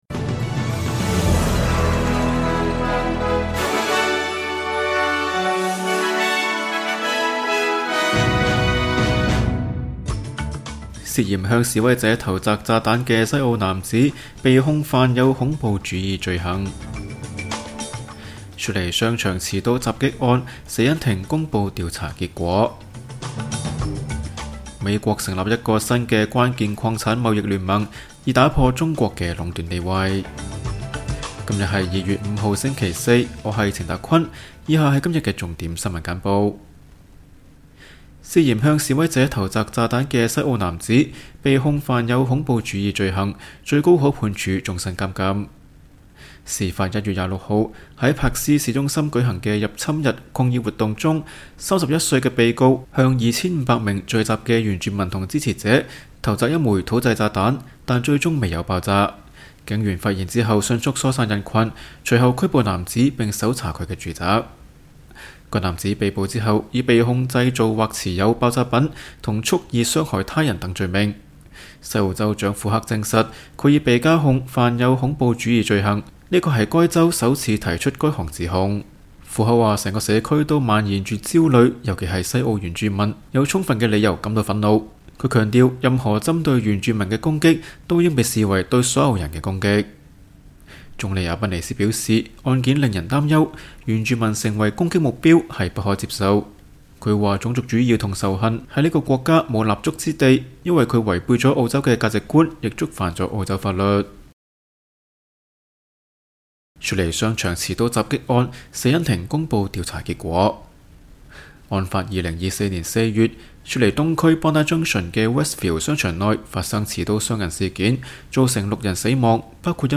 請收聽本台為大家準備的每日重點新聞簡報。